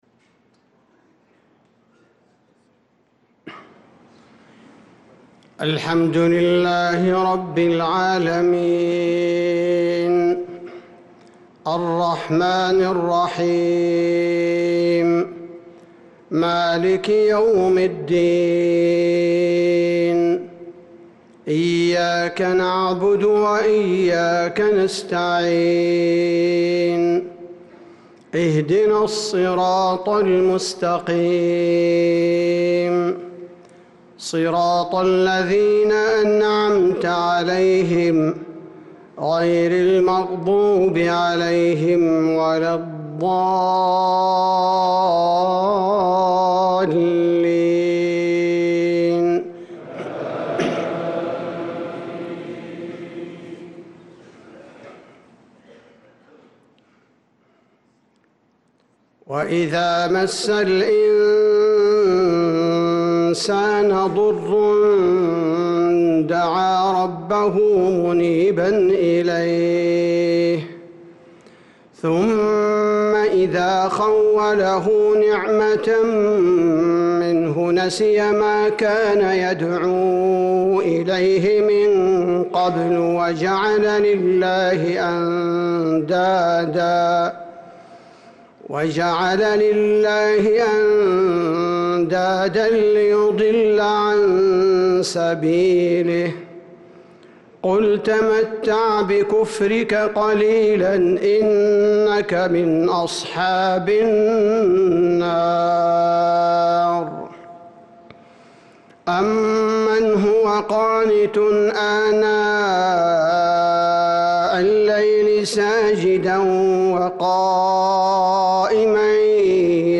صلاة العشاء للقارئ عبدالباري الثبيتي 27 شعبان 1445 هـ
تِلَاوَات الْحَرَمَيْن .